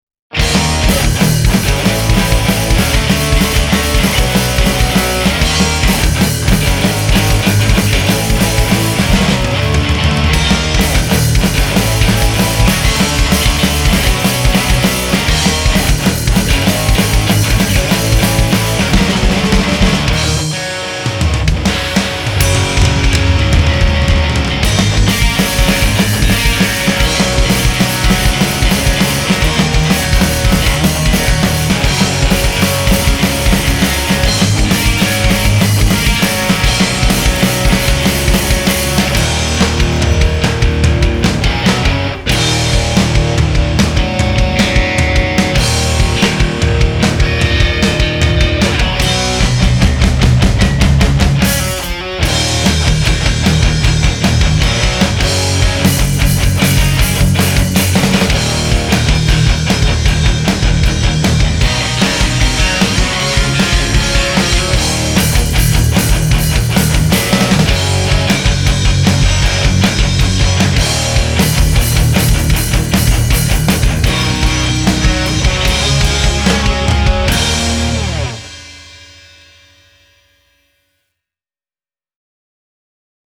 Thrash/hardcore
Rock & Roll